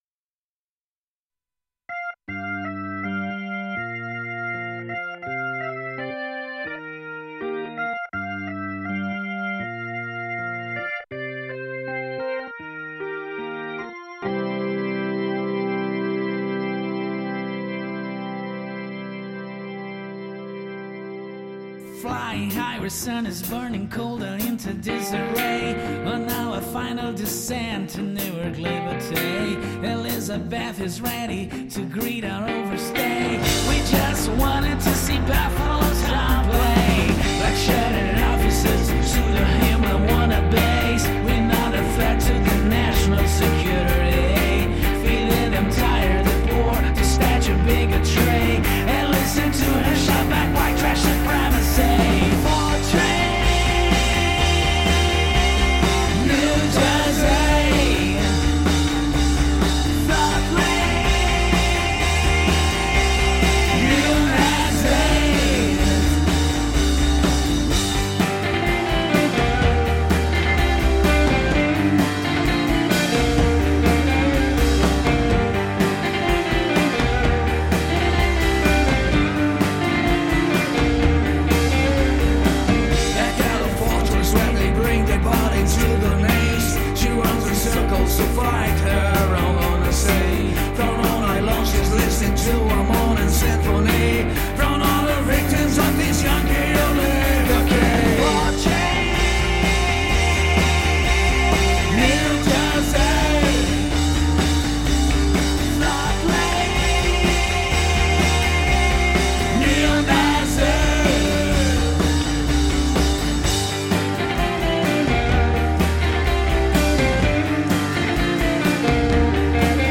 Mixing with Headphones only.
I want to continue my mixes on new songs using headphones only ...its gonna be a hard task but if you guys could help me once again on which frequencies I need to cut/boost I think I can get closer to a good mix until my monitors get back. Here's the song for a review "Fortress NJ".